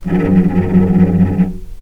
healing-soundscapes/Sound Banks/HSS_OP_Pack/Strings/cello/tremolo/vc_trm-F#2-pp.aif at b3491bb4d8ce6d21e289ff40adc3c6f654cc89a0
vc_trm-F#2-pp.aif